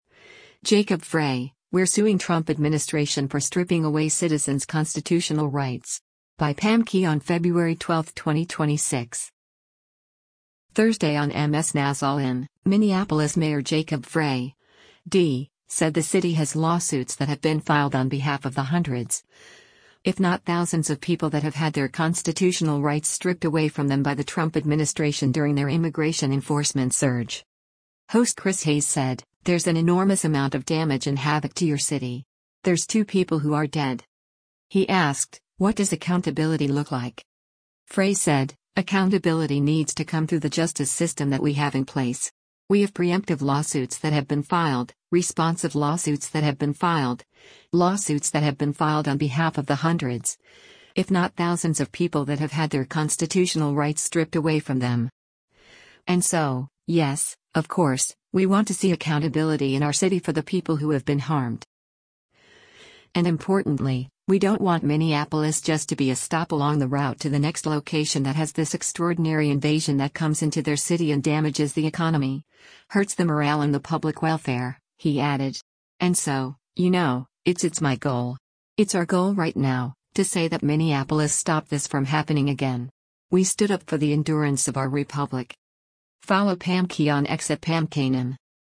Host Chris Hayes said, “There’s an enormous amount of damage and havoc to your city. There’s two people who are dead.”